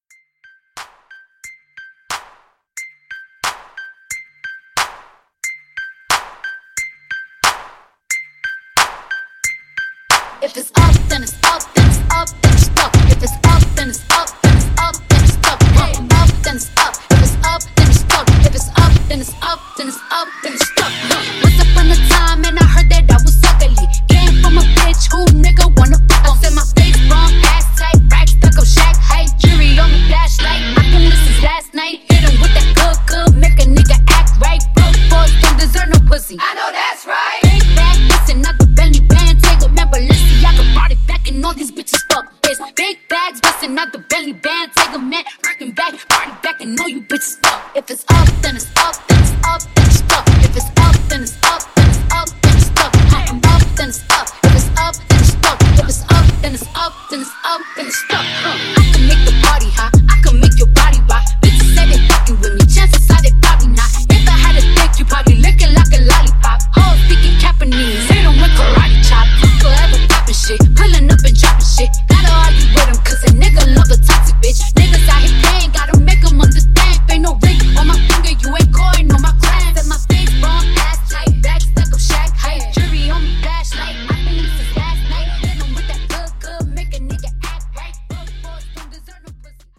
Genre: 80's
Clean BPM: 95 Time